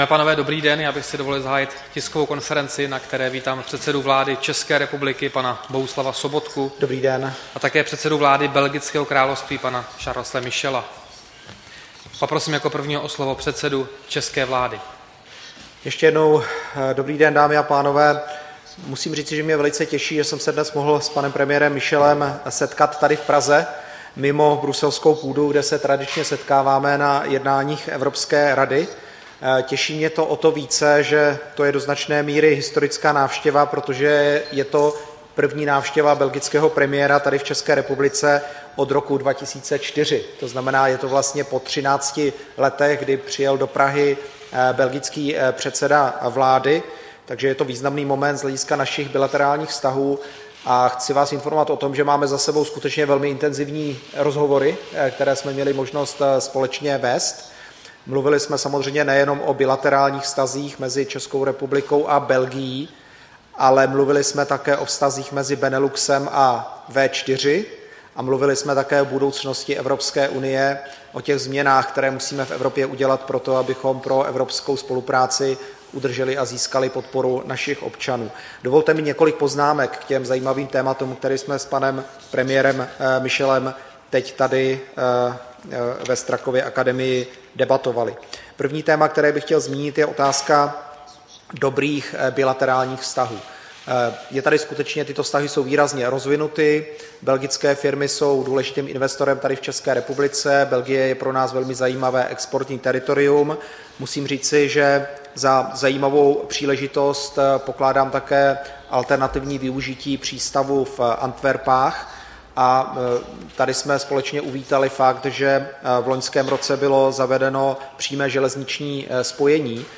Tisková konference po jednání předsedy vlády Sobotky s premiérem Belgického království Michelem, 4. května 2017